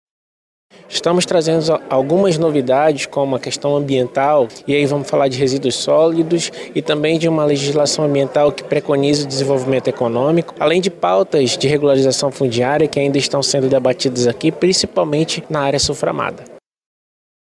Na ocasião foram debatidas pautas como a Criação da lei municipal de incentivos fiscais para indústrias da Zona Franca, implementação de programa de compras regionais, revitalização do Centro, reordenamento dos resíduos sólidos, entre outras, como destaca o líder da Secretaria Municipal de Trabalho, Empreendedorismo e Inovação (Semtepi), Radyr Jr.
Sonora-Radyr-Junior-Secretario-Semtepi.mp3